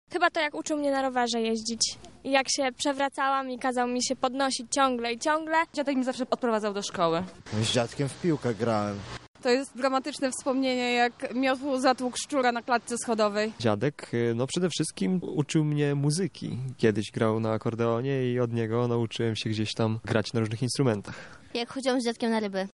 Zapytaliśmy lublinian jakie mają wspomnienia, dotyczące ich dziadków.
dzień-dziadka-sonda.mp3